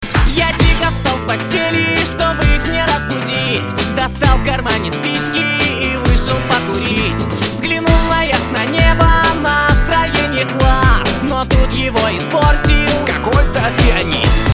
Психоделическая композиция